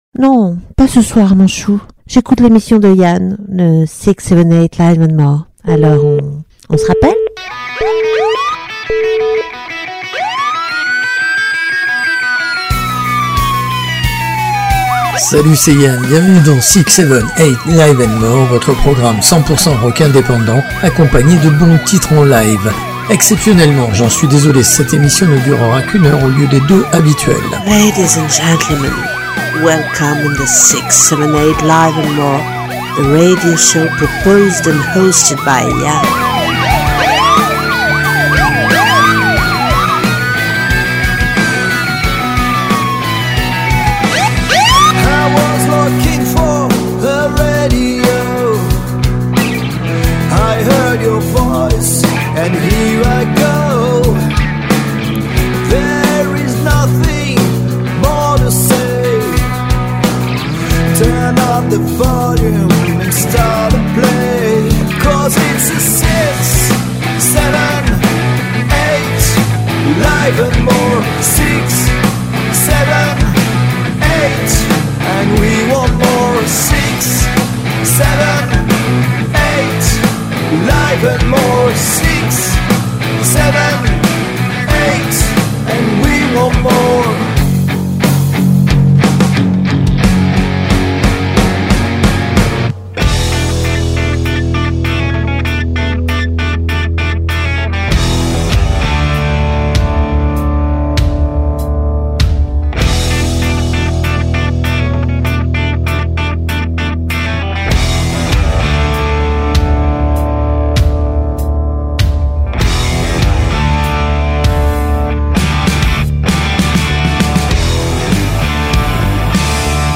Le plein de nouveautés et de découvertes rock alternatif de la semaine